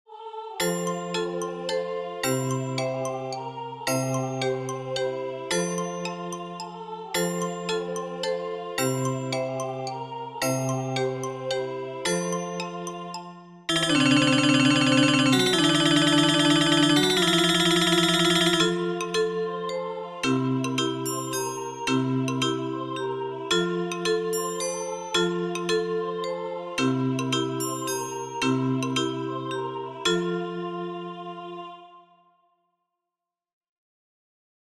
Arranjaments per a instruments Orff - Teler de música